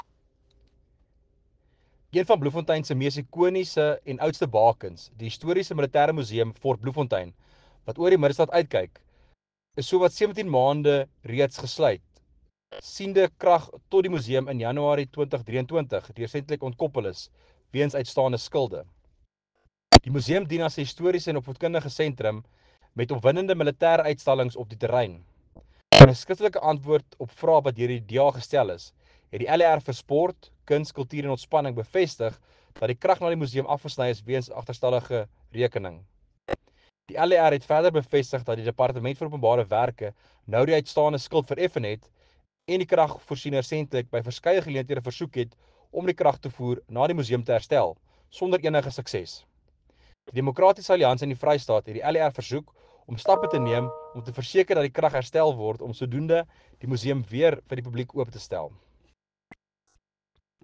Afrikaans soundbites by Werner Pretorius MPL and images here, here and here